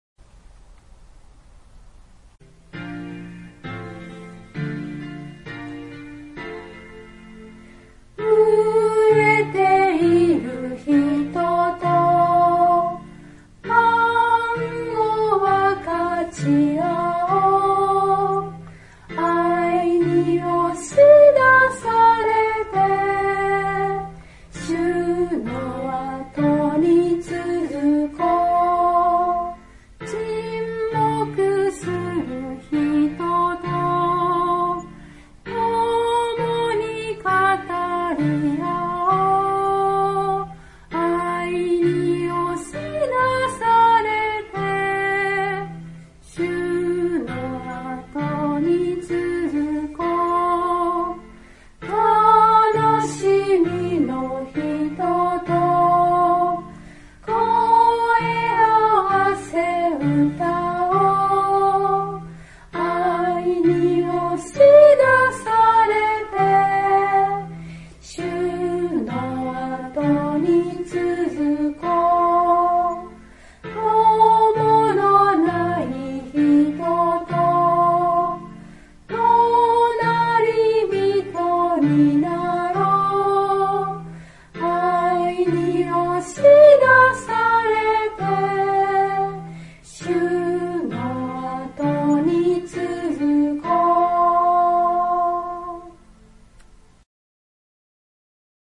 飢えている人と（讃２１-486） God bless you）より 唄
（徳島聖書キリスト集会集会員）